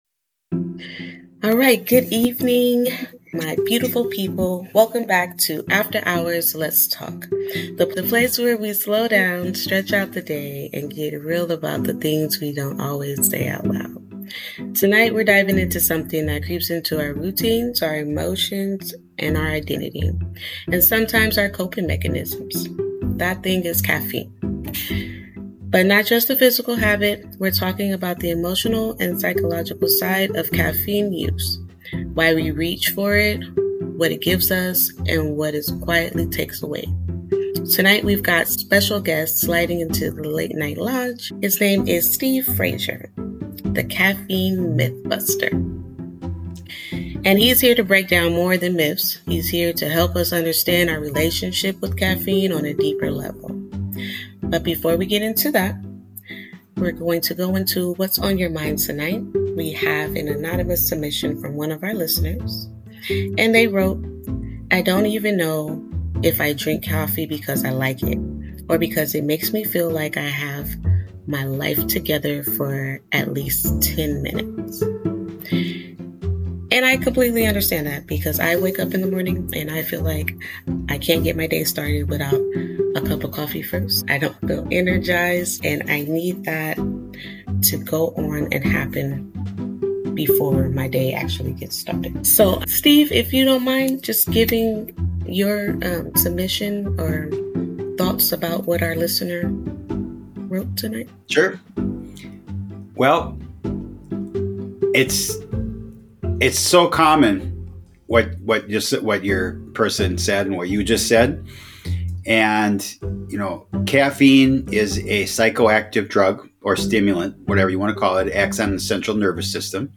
A chill free flowing late night podcast with intimate reflections on life, creativity, mental health, and the weirdness of being human.